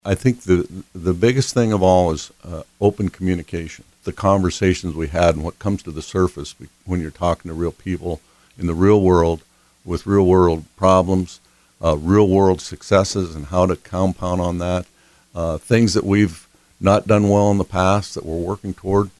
During a stop at the DRG Media Group studios (March 18, 2025), Rhoden said the hope is to bring what towns are doing– or wanting to do– together with what state government is working on in order to access the best economic development opportunities possible.